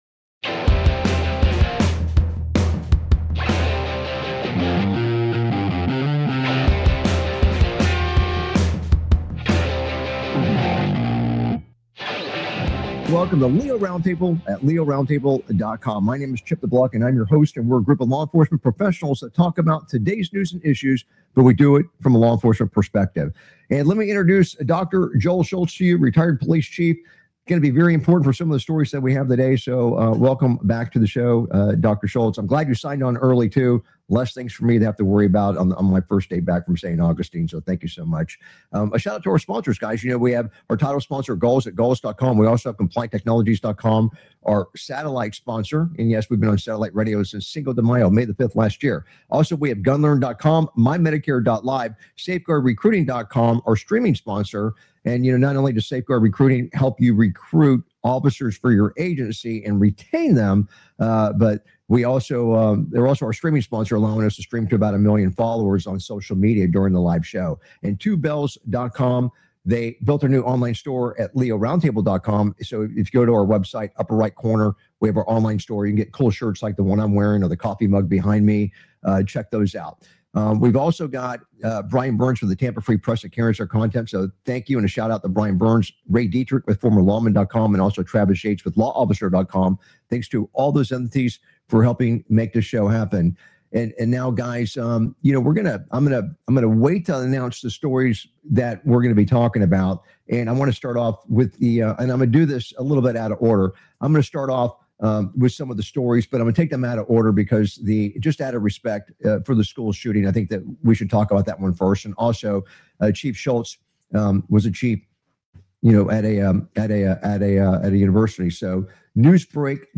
Talk Show Episode, Audio Podcast, LEO Round Table and S11E078, Prosecutor Charges ICE Agent But Neglects To Charge Reporters Attackers on , show guests , about Prosecutor Charges ICE Agent,S11E078 Prosecutor Charges ICE Agent But Neglects To Charge Reporters Attackers, categorized as Entertainment,Military,News,Politics & Government,National,World,Society and Culture,Technology,Theory & Conspiracy